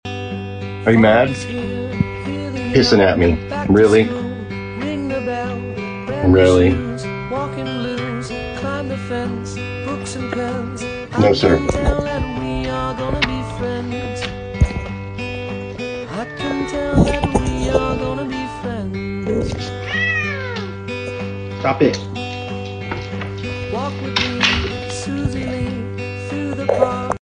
So he hissed and growled at me.